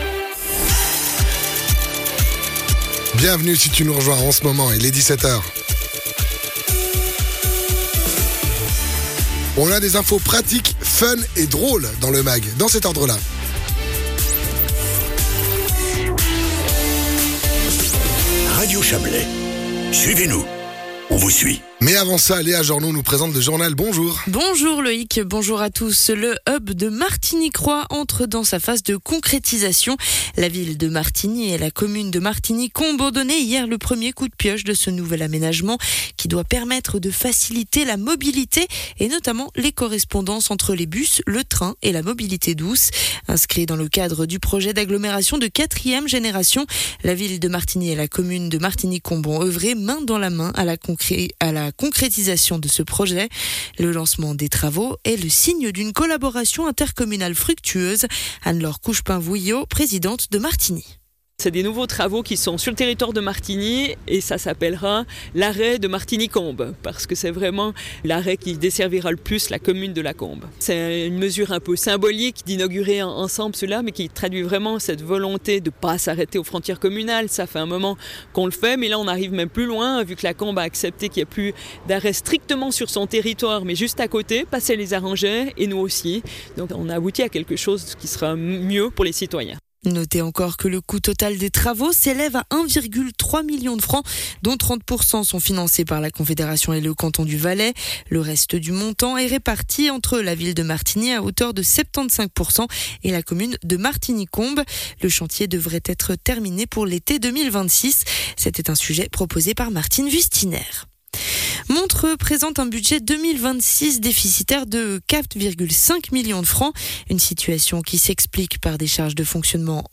Les infos de 17h00 du 07.11.2025